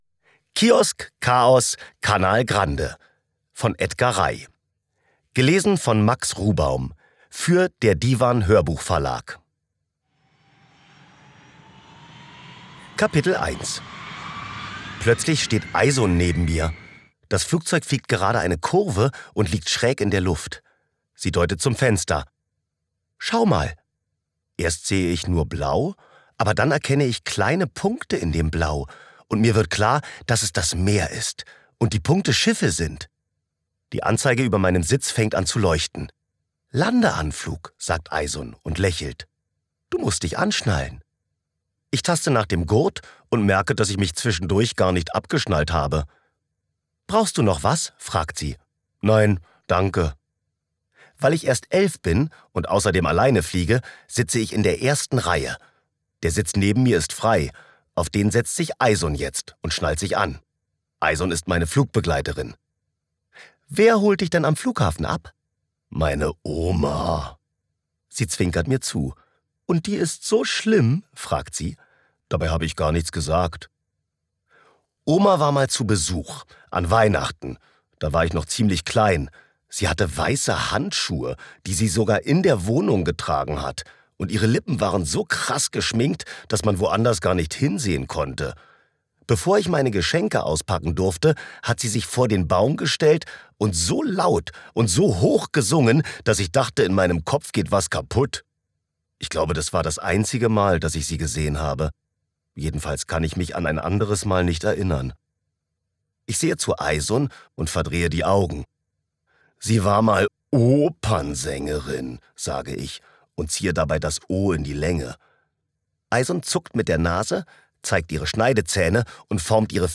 Audio/Hörbuch